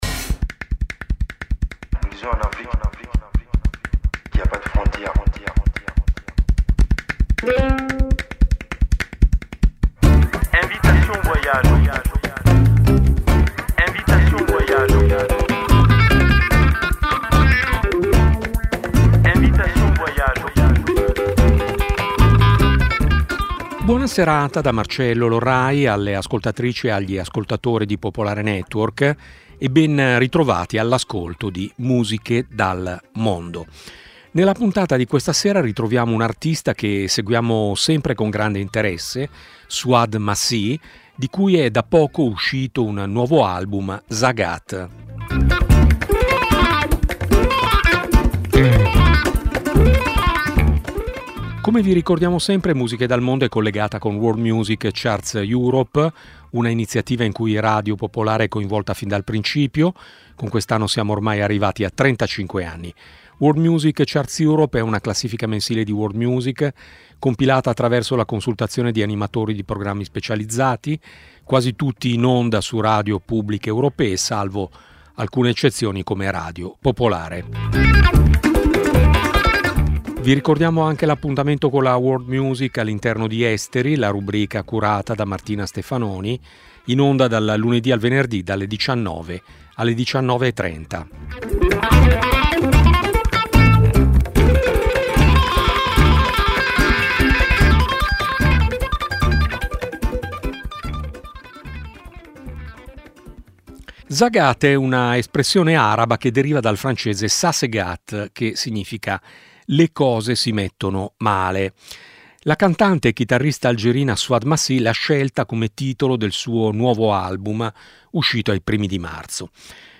Musiche dal mondo è una trasmissione di Radio Popolare dedicata alla world music, nata ben prima che l'espressione diventasse internazionale.
Un'ampia varietà musicale, dalle fanfare macedoni al canto siberiano, promuovendo la biodiversità musicale.